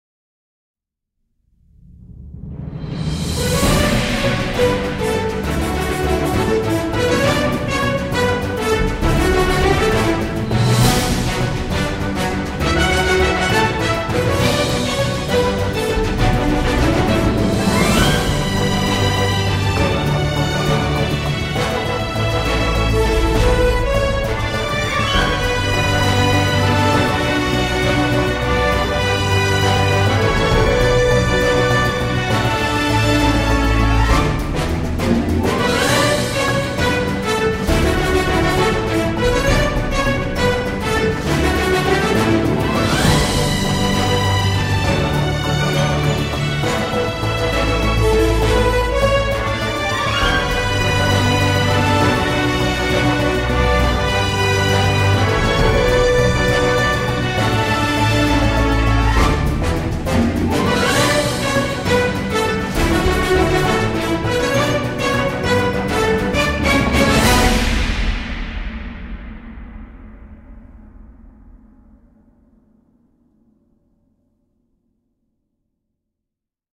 مغامرات جول فيرن - الحلقة 1 مدبلجة